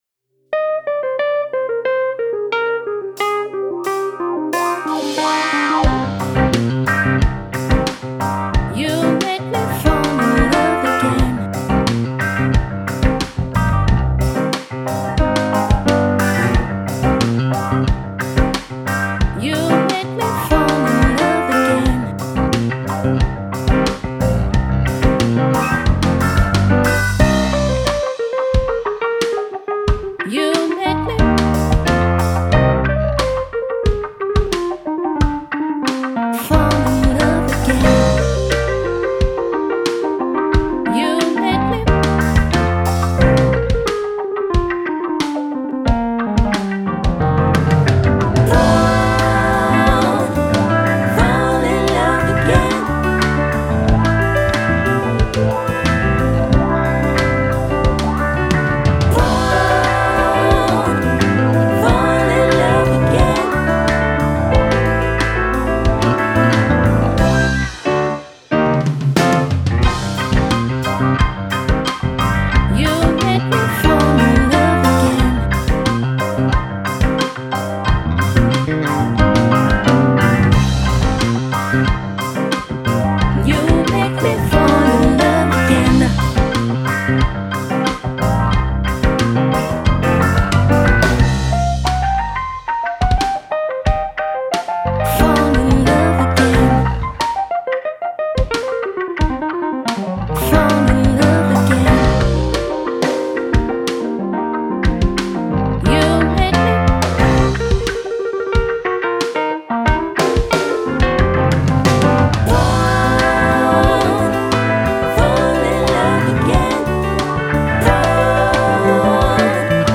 Vocal Sample
Keys
Guitar
Bass
Drums